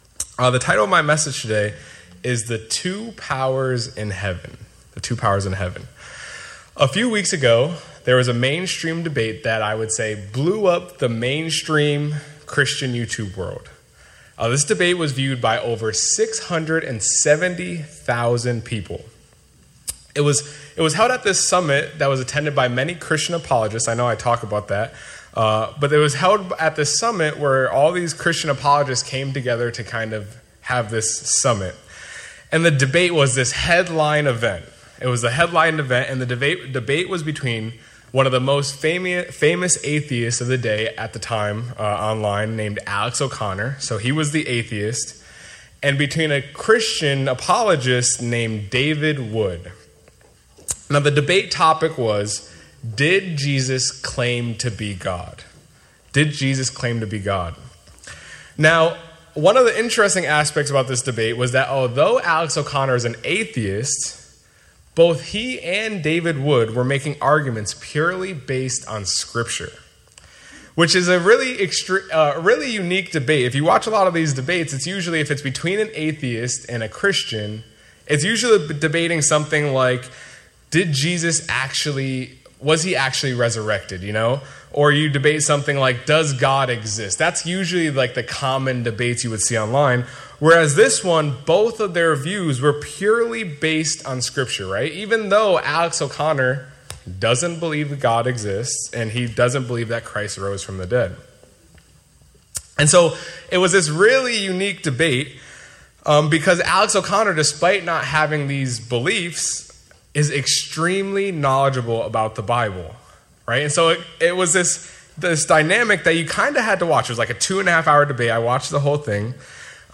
Sermons
Given in New York City, NY